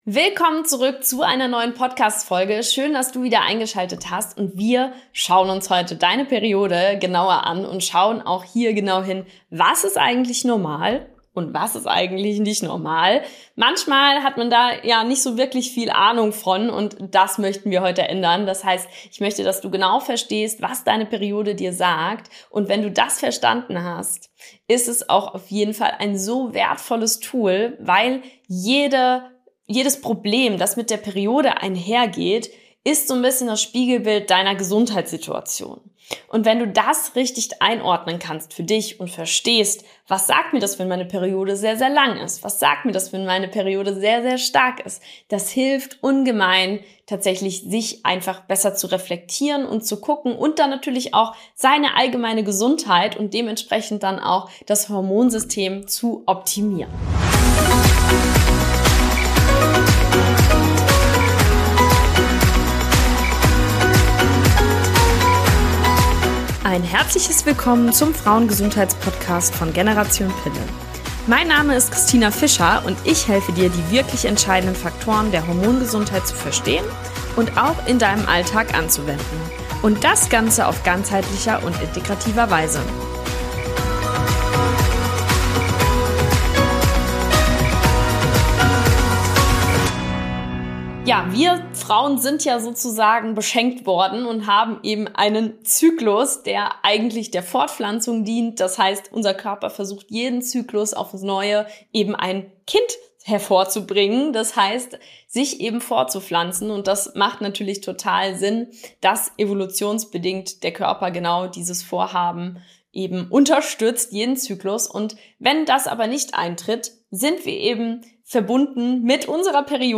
Erwarte inspirierende Interviews und